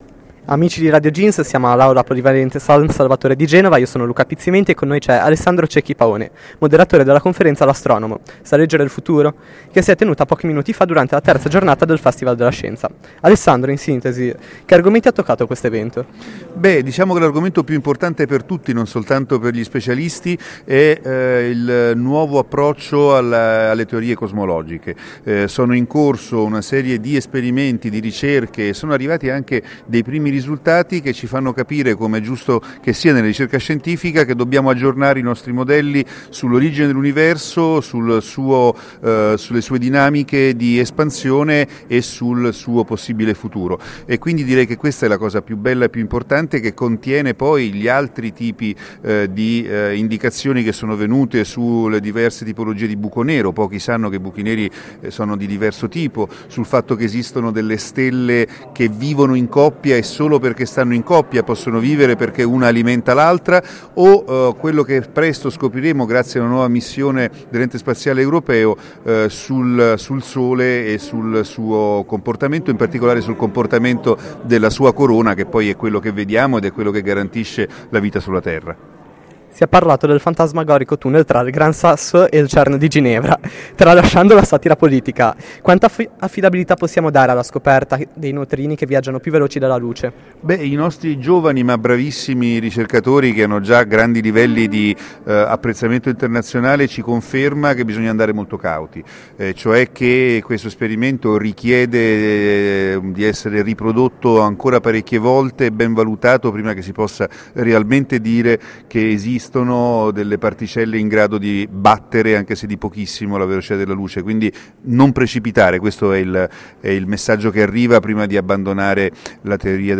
Intervista a Alessandro Cecchi Paone
Intervista in occasione del festival della scienza